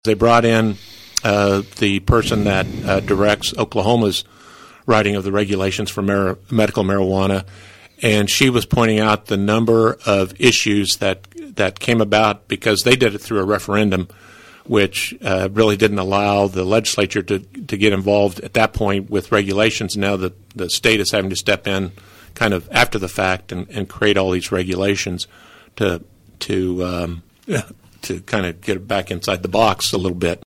The candidates for the Kansas House 60th District position clashed on several topics during KVOE’s final Candidate Forum of this general election cycle.